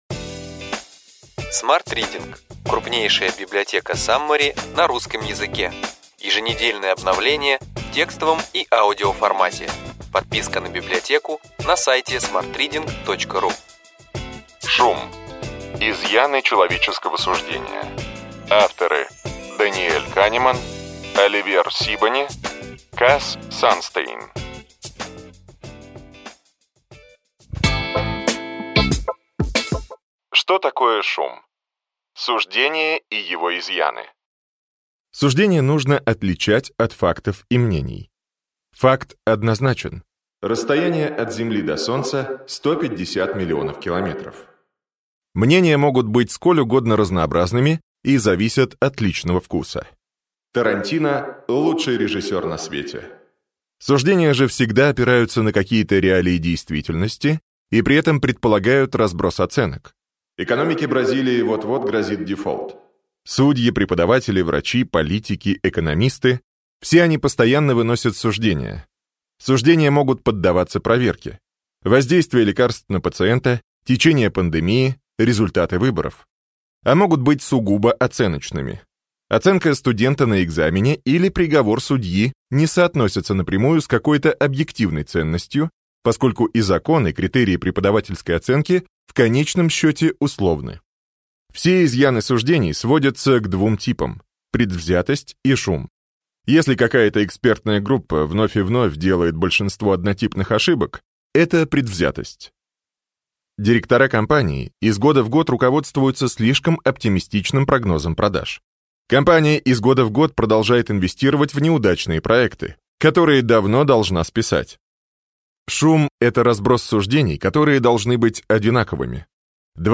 Аудиокнига Ключевые идеи книги: Шум. Изъяны человеческого суждения.